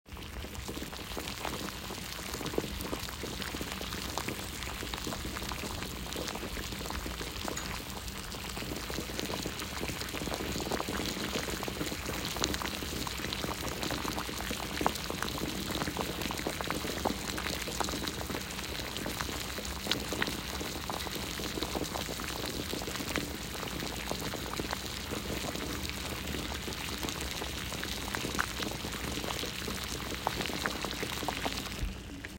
boiling-water.m4a